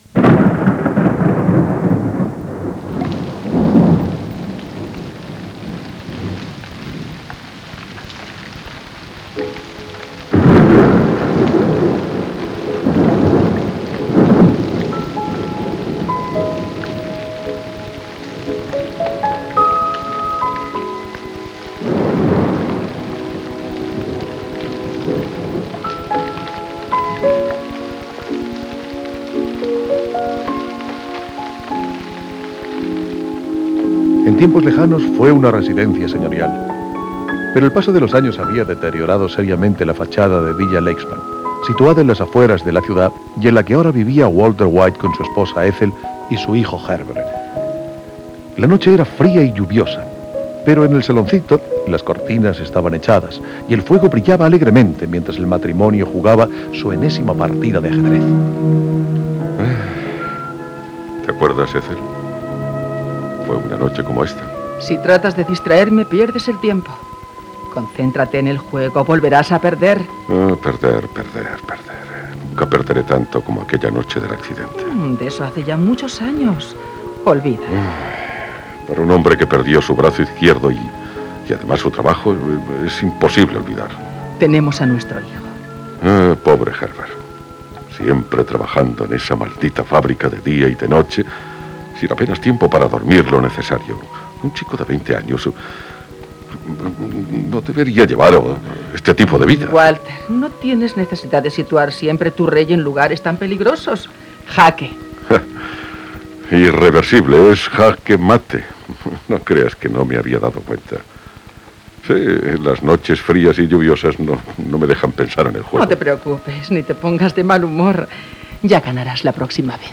adaptació radiofònica
Gènere radiofònic Ficció